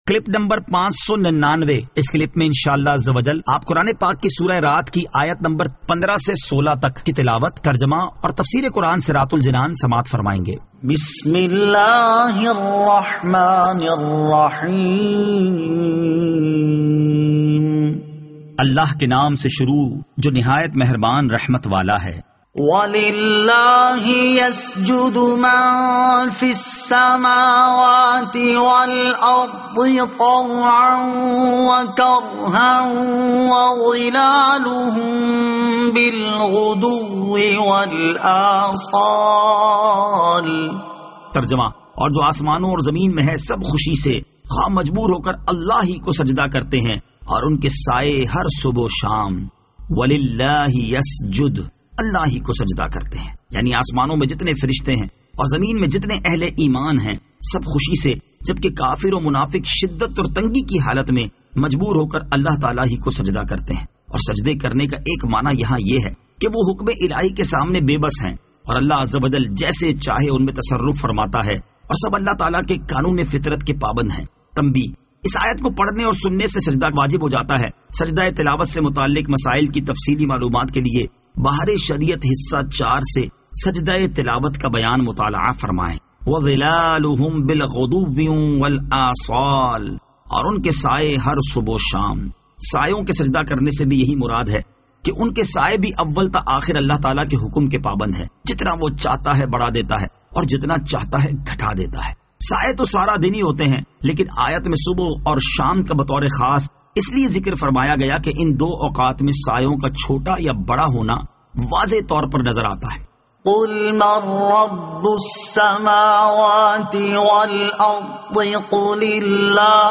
Surah Ar-Rad Ayat 15 To 16 Tilawat , Tarjama , Tafseer